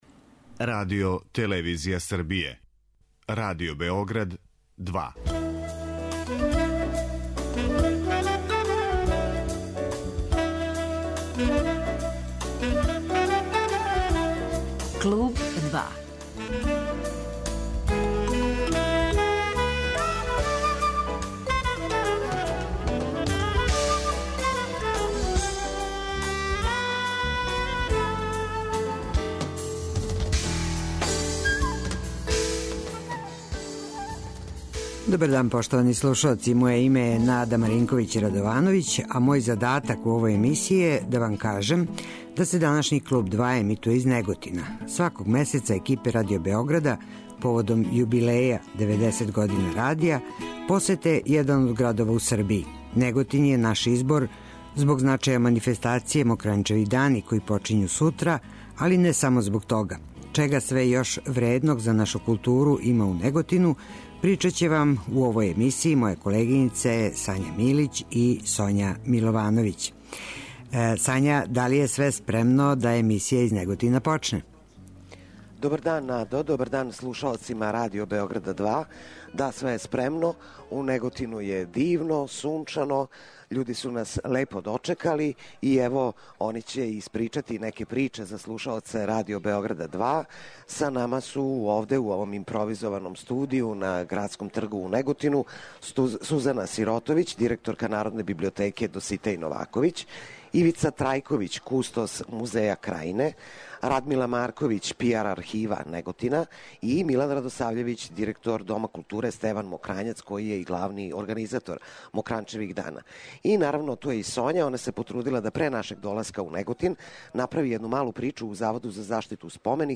У оквиру обележавања јубилеја - 90 година Радио Београда - емисију реализујемо из Неготина.